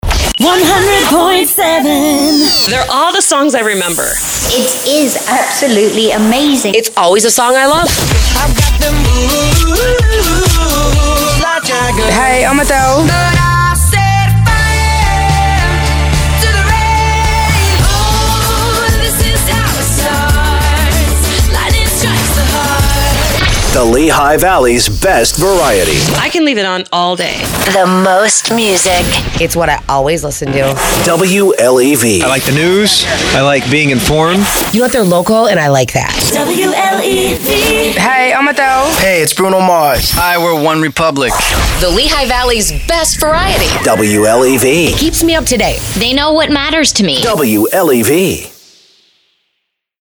AC Adult Contemporary
Great listener elements, relevant artist material and a sound that reflects what's current without being in your face. Featuring music imaging, lifestyle sweepers, on air work parts, jingles and music beds.